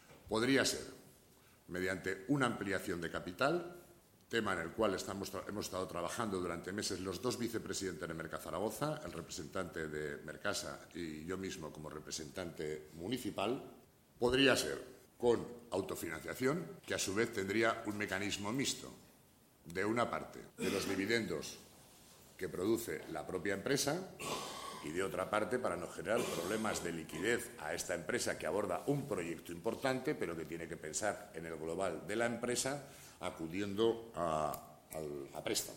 Así lo explica Pérez Anadón
El vicepresidente de Mercazaragoza señala también cómo se va a financiar esta obra